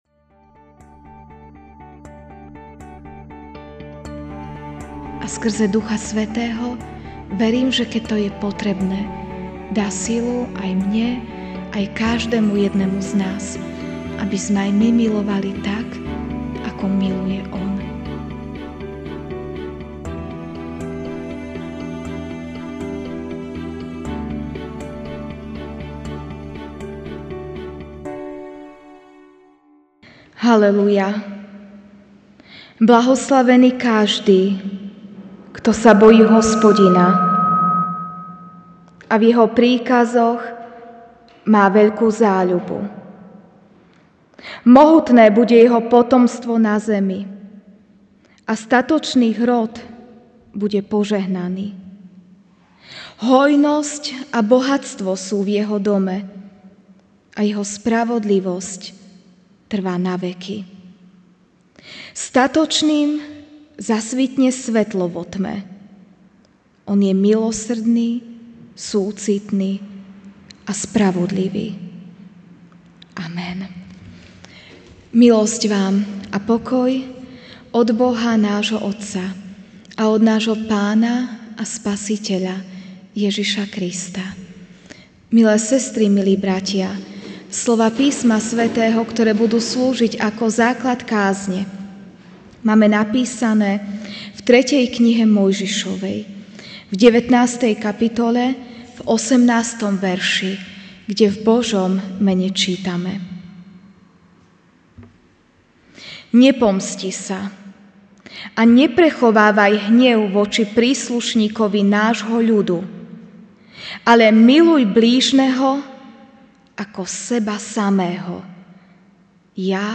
Večerná kázeň: Môj blížny (3 Mojžišova 19,18)